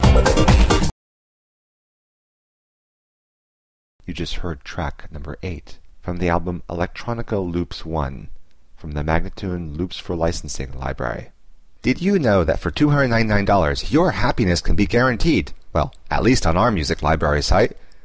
Instrumental samples in many genres.
135-C-ambient:teknology-1031